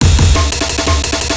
subsonic_amen.wav